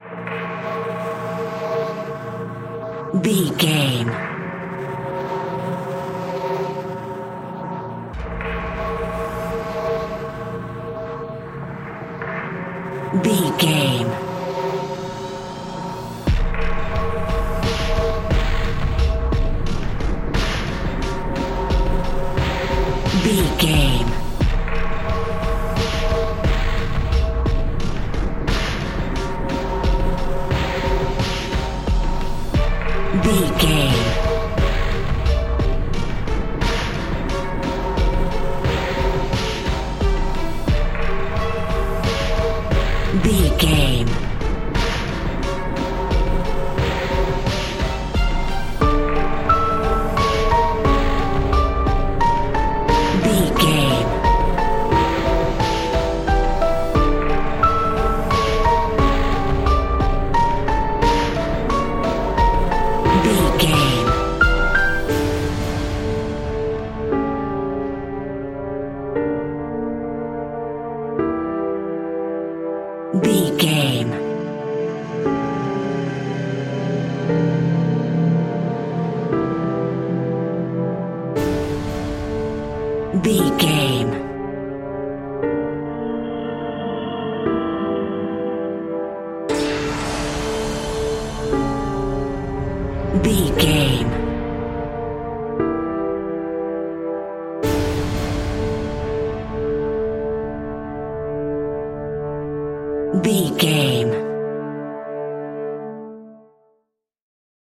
In-crescendo
Aeolian/Minor
scary
tension
ominous
suspense
haunting
eerie
strings
synth
keyboards
ambience
pads